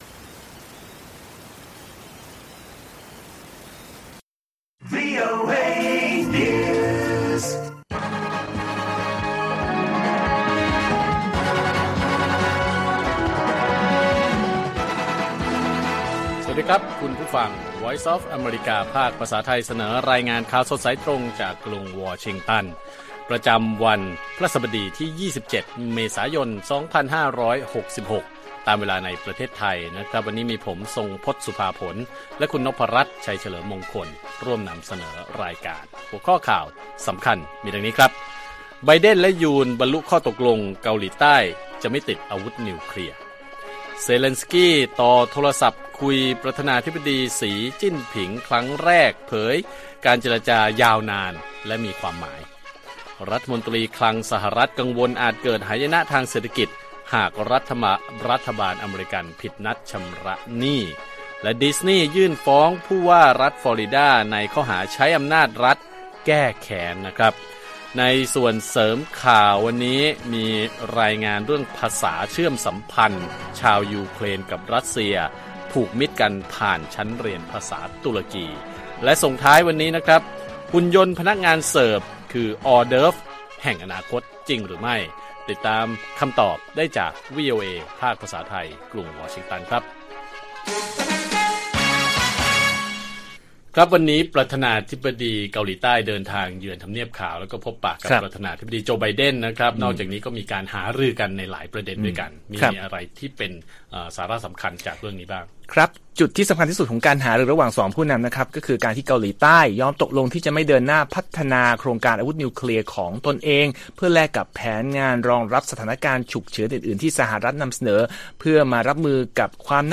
ข่าวสดสายตรงจากวีโอเอ ไทย พฤหัสฯ 27 เม.ย. 2566